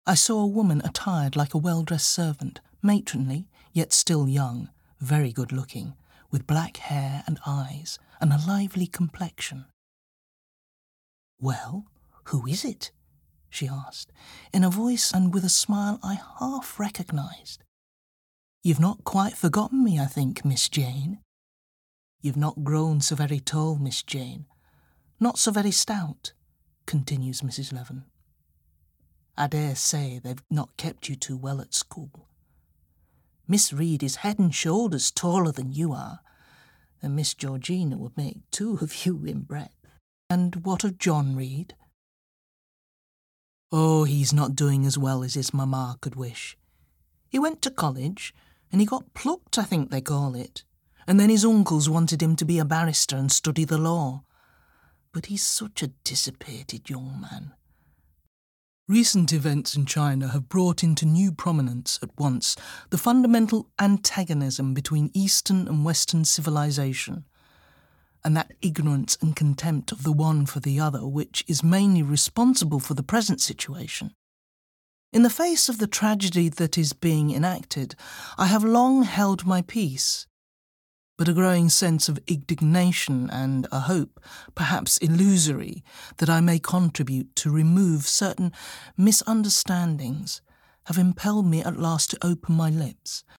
30s-60s. Female. West Yorkshire/RP.